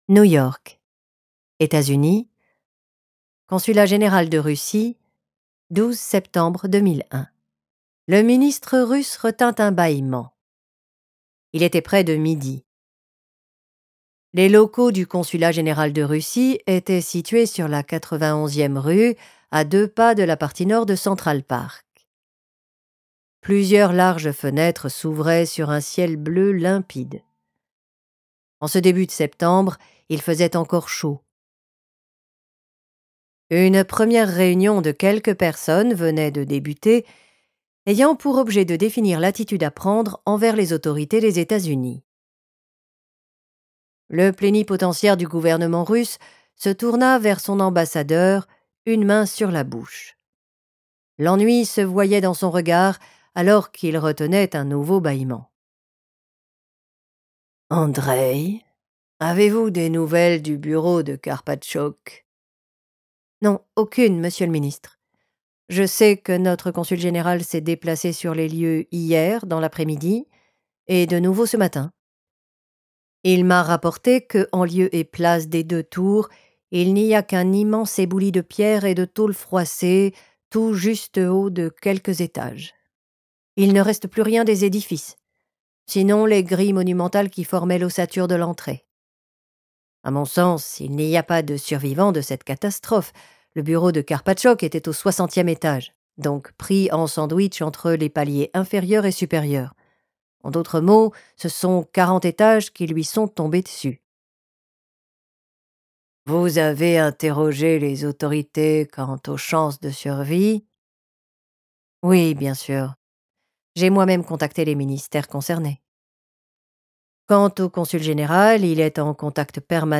Les Larmes d’Ormuz, livre audio | AB-Story